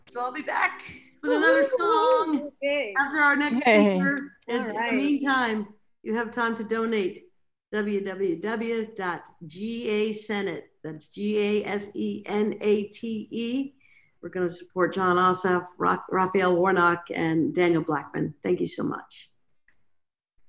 (audio captured from zoon meeting)
05. talking with the crowd (emily saliers) (0:20)